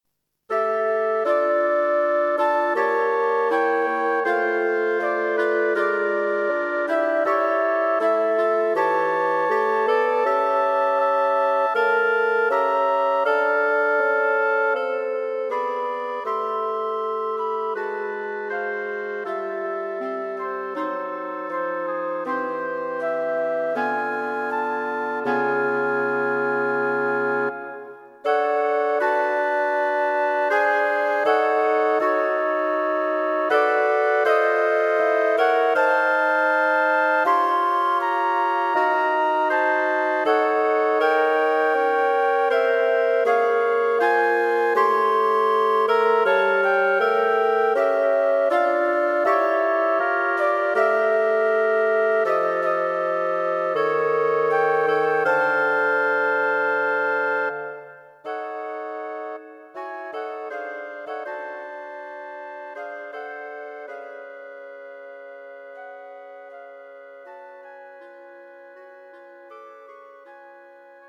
Wind Quartet for Concert performance